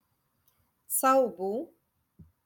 Moroccan Dialect- Rotation Three- Lesson Seventeen